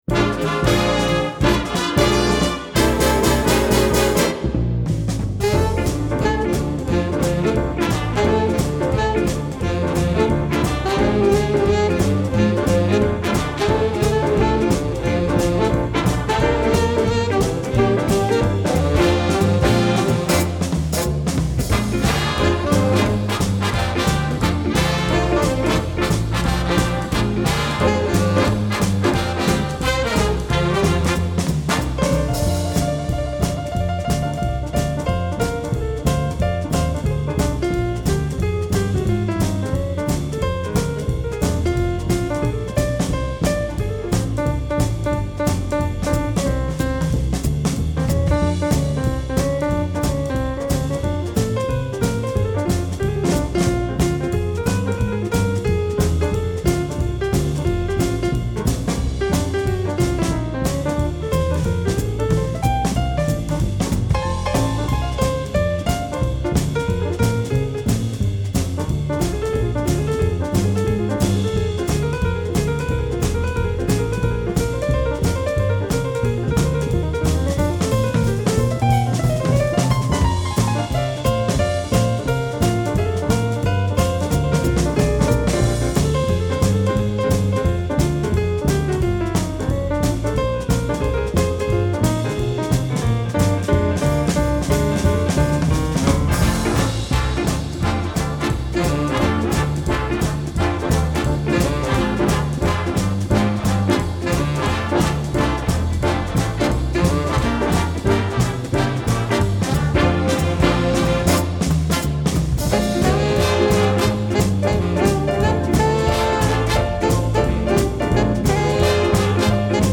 Recorded in Winchester, VA.